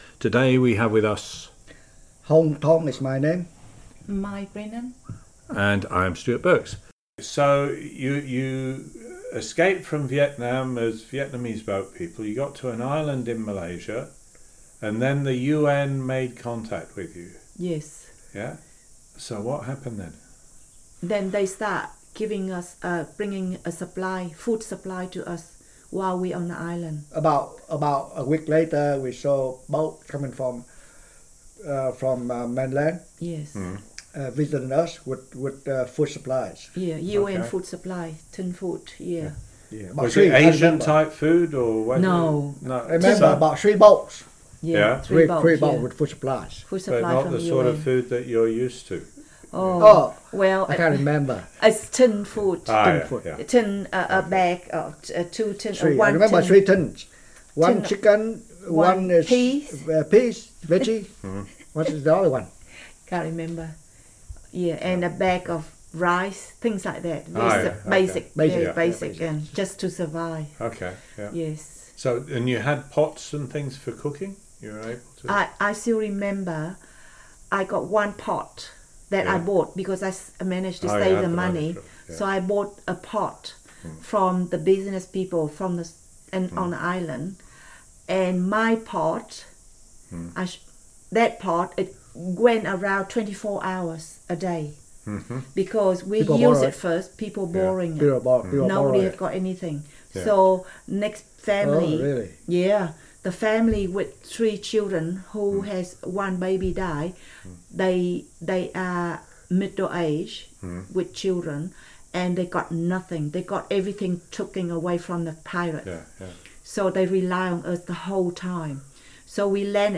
Audio depicts interview